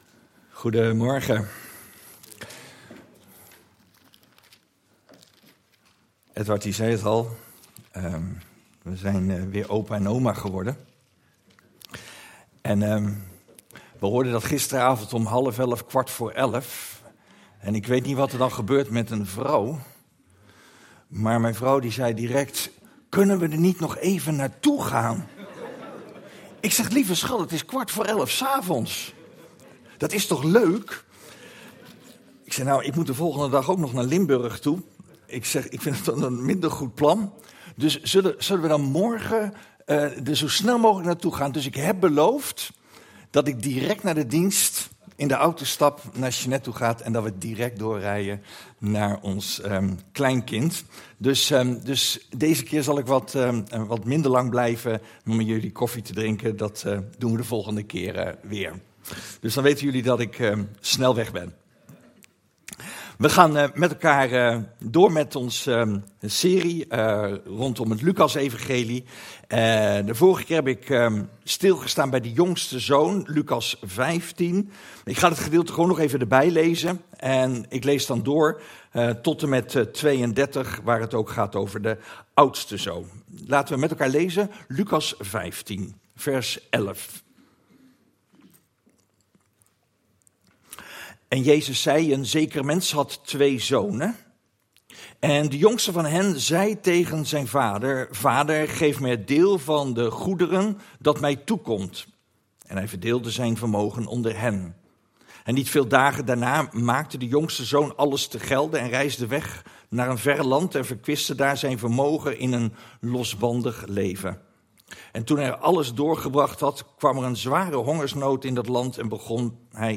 Passage: Lukas 15:25-32 Dienstsoort: Eredienst « Wandel met Jezus Hoelang hinkt u nog op twee gedachten?